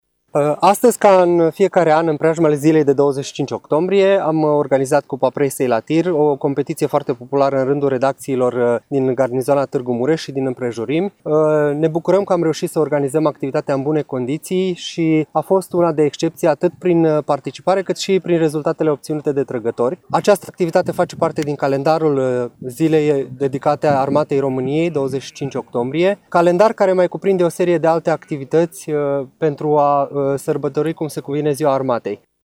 În cadrul manifestărilor premergătoare sărbătoririi Zilei de 25 Octombrie – Ziua Armatei României, militarii Garnizoanei Tirgu-Mureș au organizat, astăzi, o nouă ediţie a „Cupei Presei la TIR”.